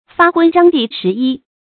发昏章第十一 fā hūn zhāng dì shí yī 成语解释 昏头昏脑的风趣话。